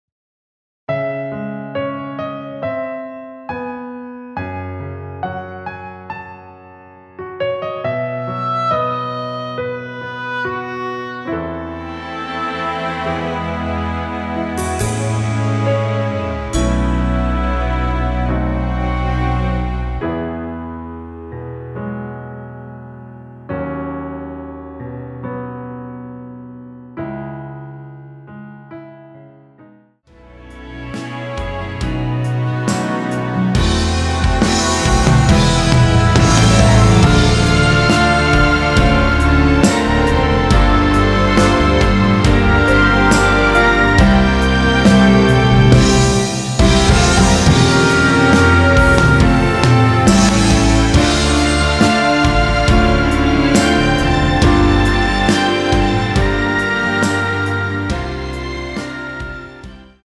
원키에서(-1)내린 MR입니다.
앞부분30초, 뒷부분30초씩 편집해서 올려 드리고 있습니다.